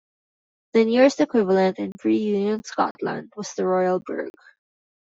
Read more Noun Name Name Name Frequency B1 Pronounced as (IPA) [uˈni̯oːn] Etymology (locomotive): Derived from the name of the only U.S. company to use that configuration.